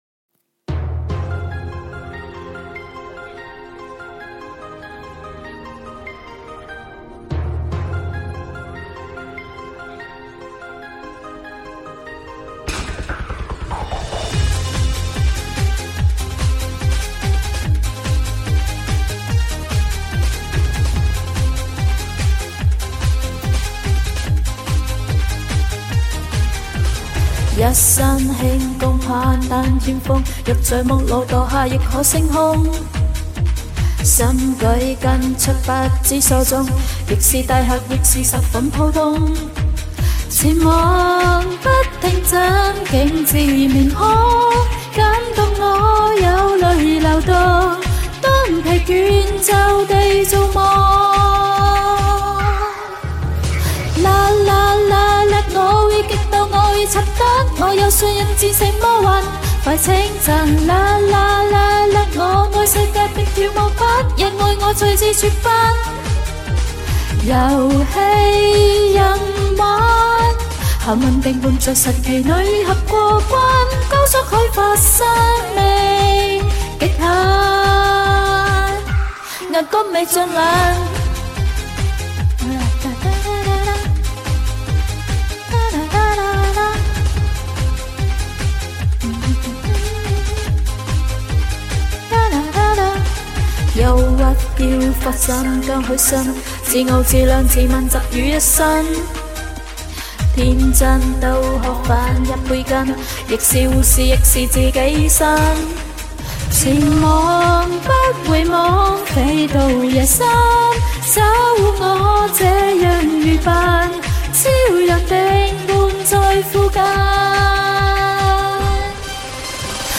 这是清晨第一声清脆悦耳的歌声